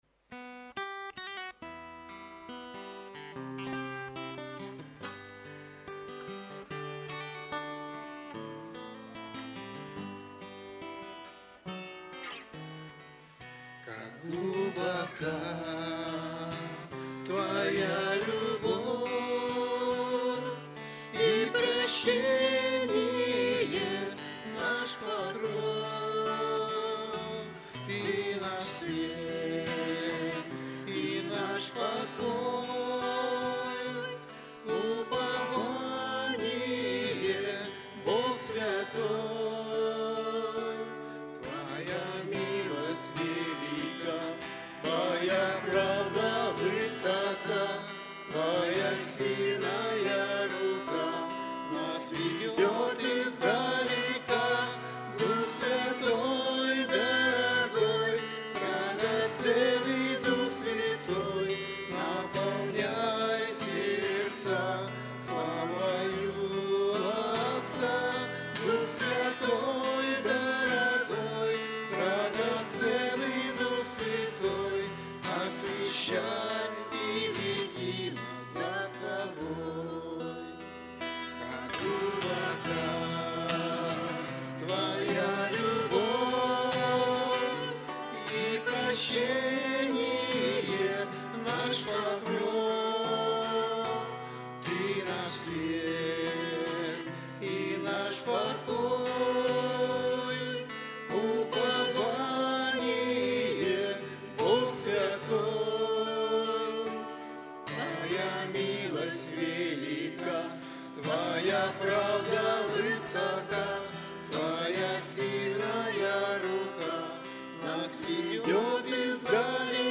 Богослужение 25.08.2024
Пение